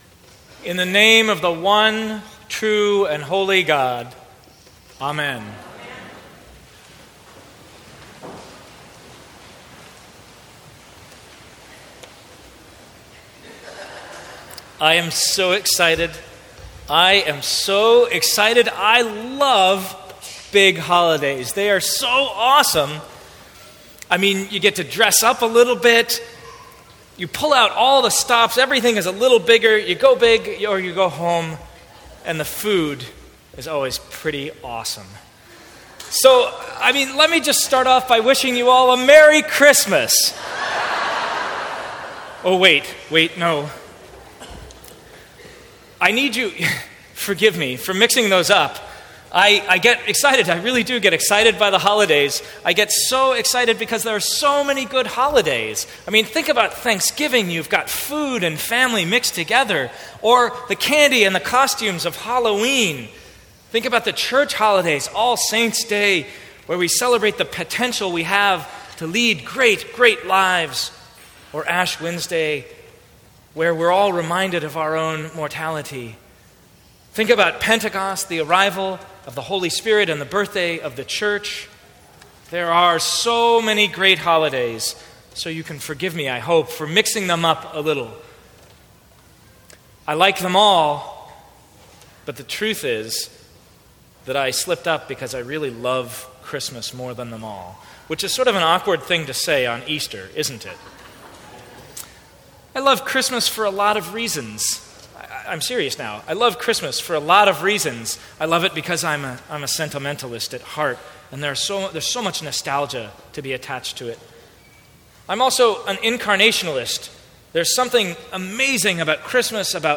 Sermons from St. Cross Episcopal Church